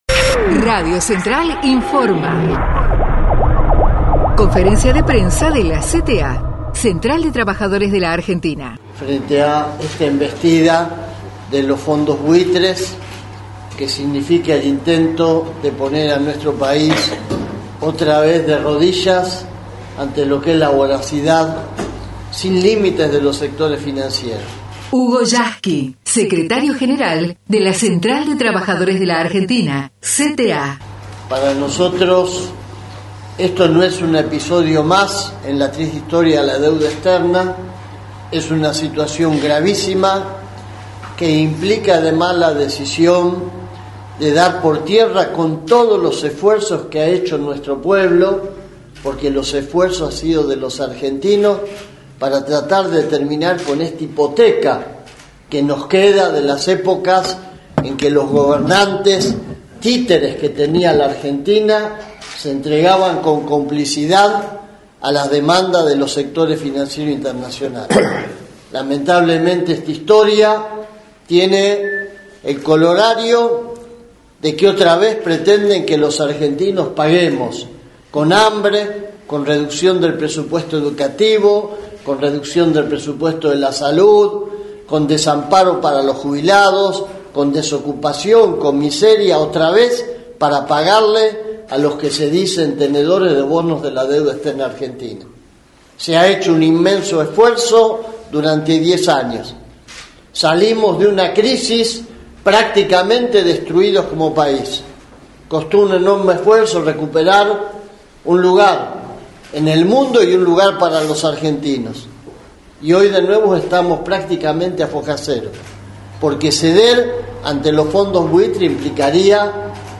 En conferencia de prensa, Hugo Yasky, acompa�ado por miembros de la conducci�n nacional de la Central y por el prestigioso intelectual brasile�o Emir Sader, conden� la extorsi�n de la justicia norteamericana.